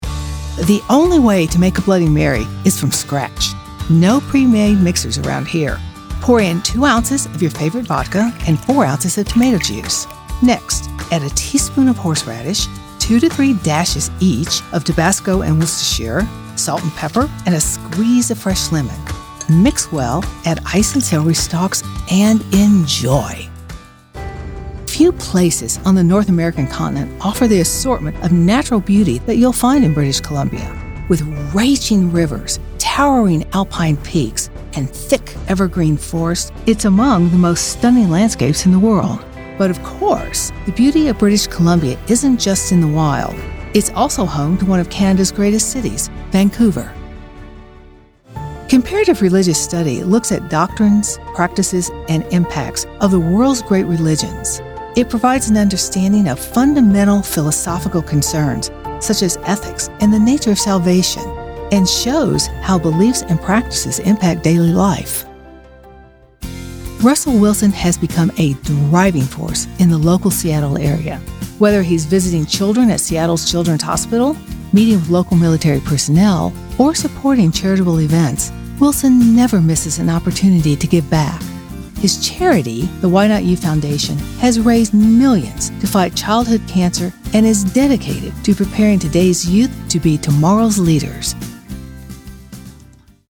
professional voice over services related to Commercials and Narrations.